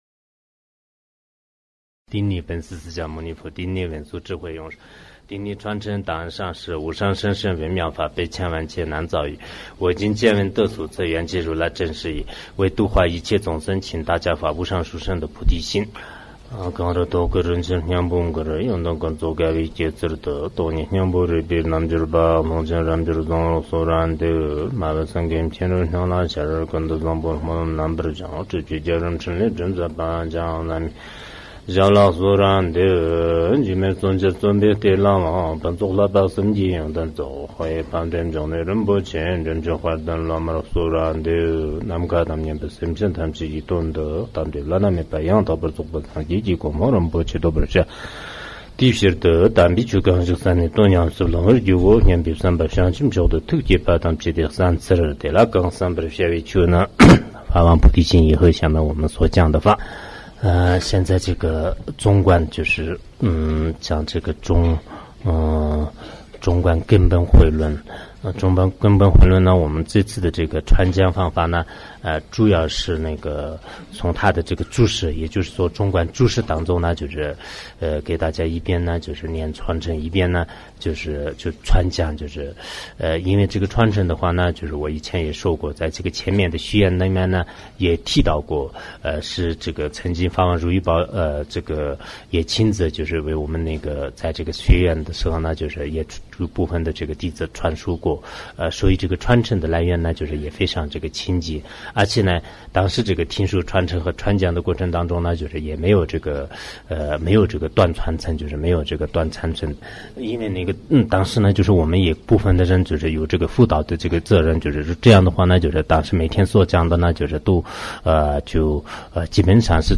中论讲解 龙树菩萨 ·造论 麦彭仁波切 · 注释 索达吉堪布 ·译讲 顶礼本师释迦